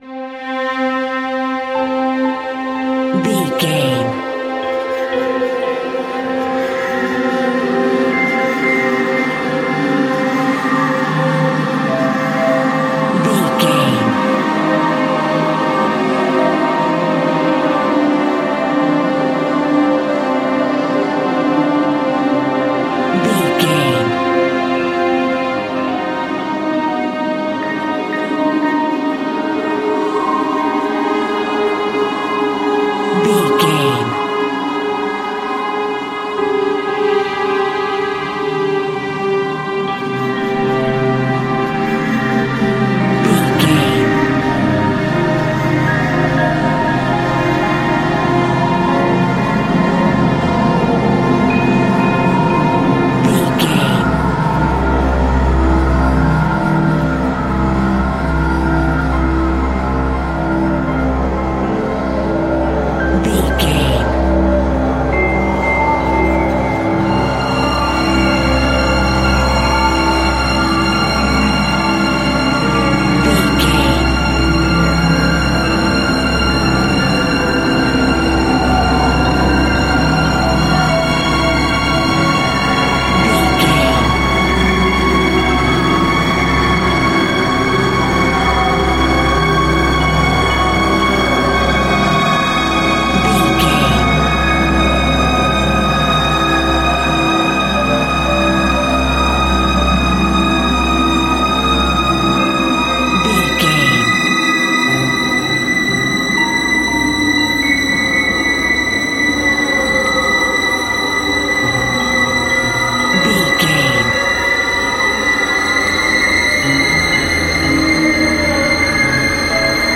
Aeolian/Minor
scary
ominous
dark
suspense
eerie
strings
piano
synth
ambience
pads